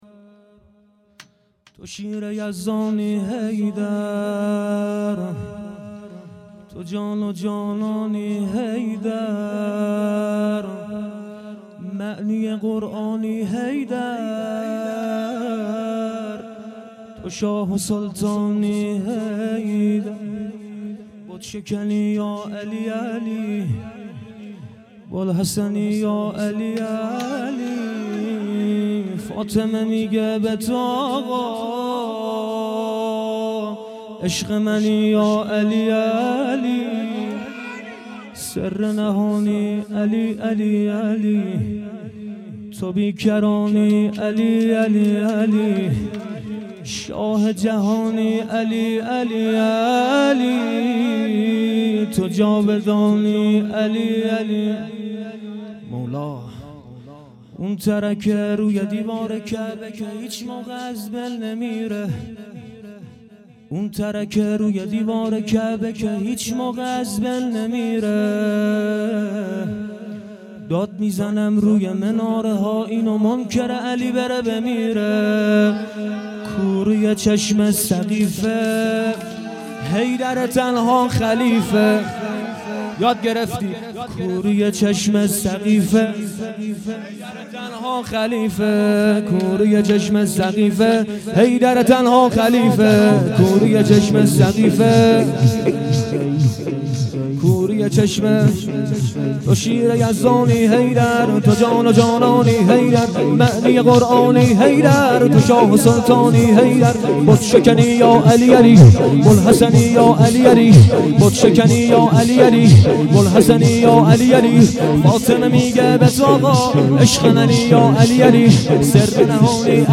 شور طوفانی حضرت امیرالمومنین ع